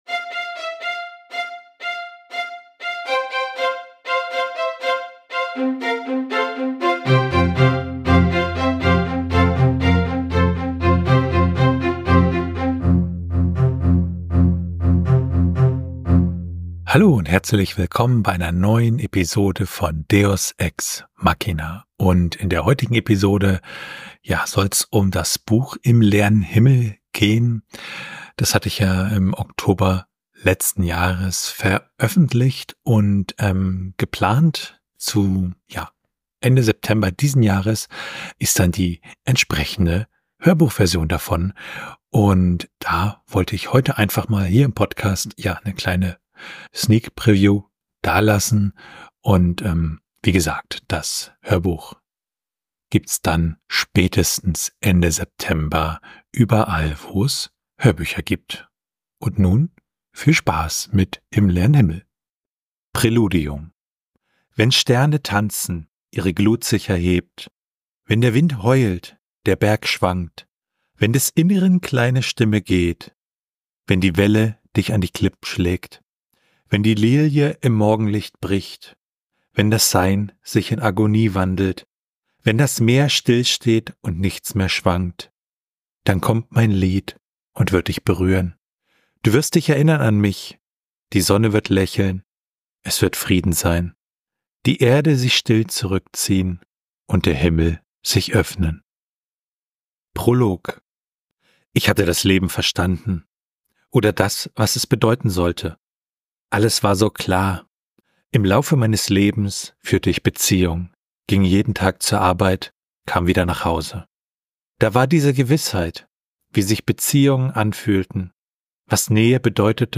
In dieser Episode von Deus ex machina gibt es ein kleines Sneak-Preview der kommenden Hörbuchfassung von Im leeren Himmel.